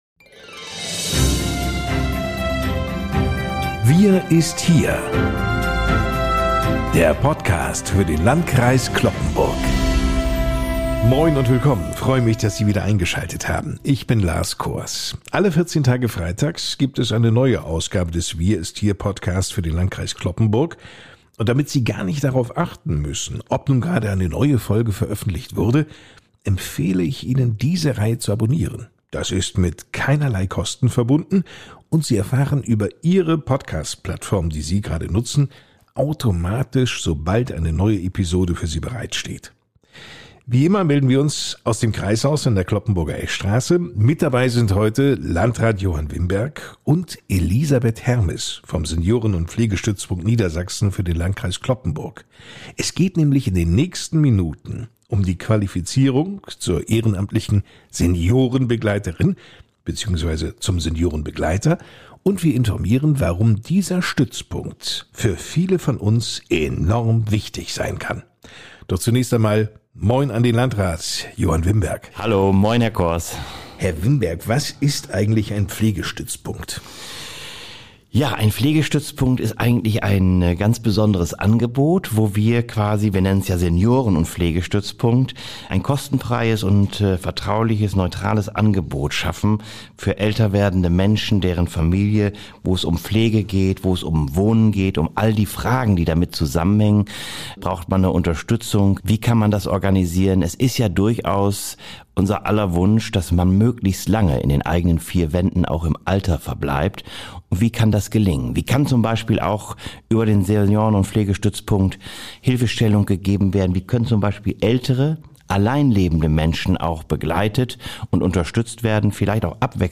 Der Podcast für den Landkreis Cloppenburg ist ein fesselndes und informatives Format, das alle 14 Tage freitags veröffentlicht wird.